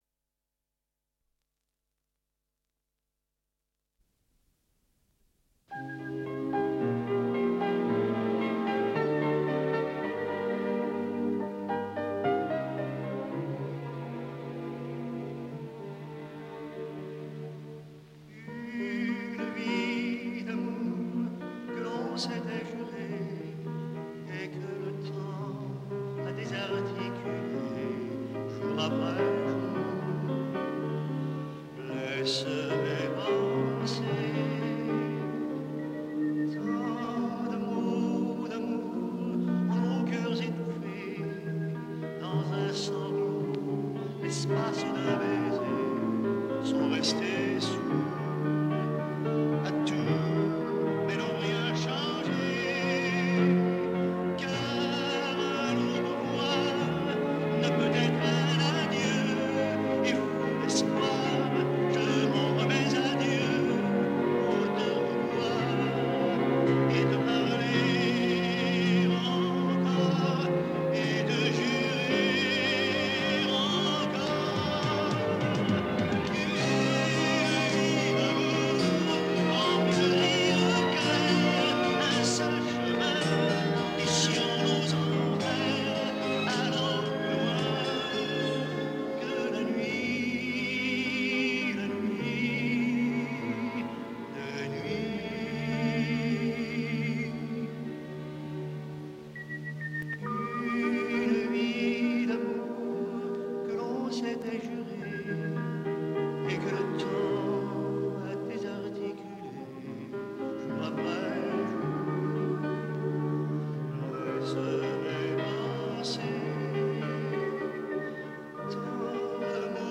Сопровождение оркестр.
Исполняет автор.